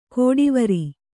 ♪ kōḍivari